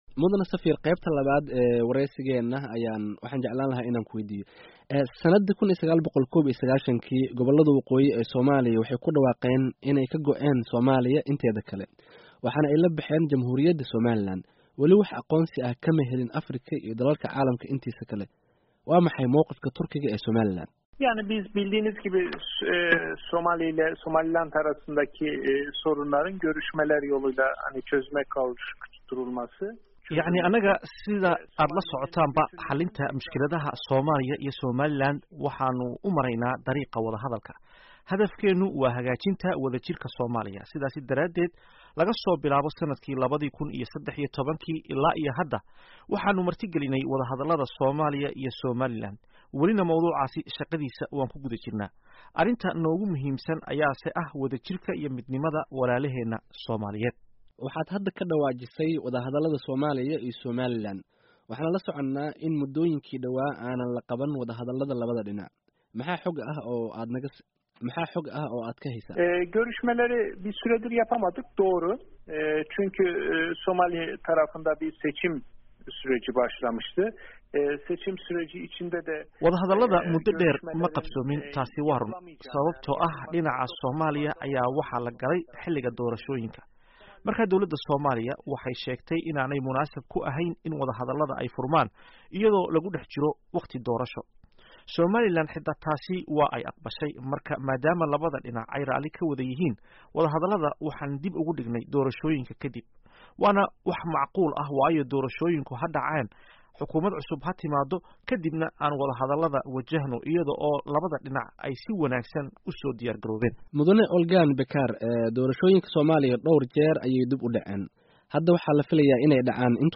Dhegayso: Qeybtii labaad ee waraysiga Danjiraha Turkiga ee Soomaaliya